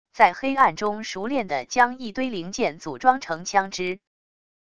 在黑暗中熟练地将一堆零件组装成枪支wav音频